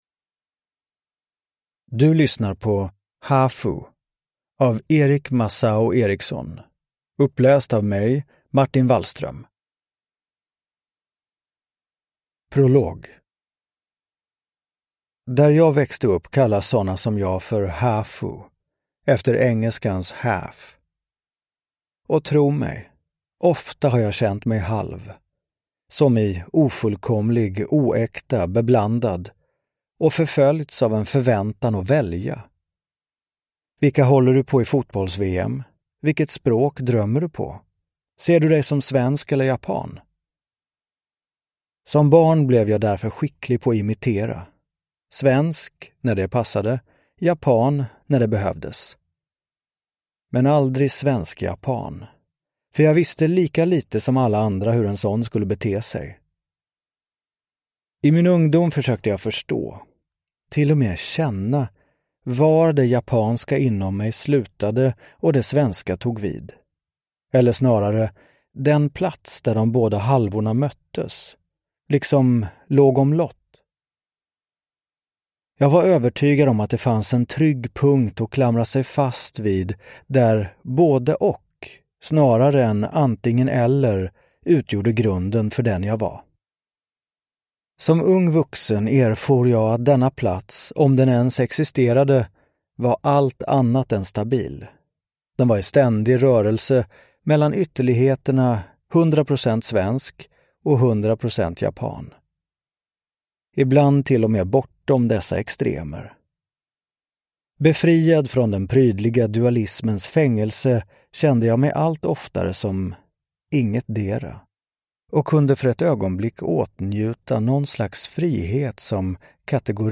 Uppläsare: Martin Wallström
Ljudbok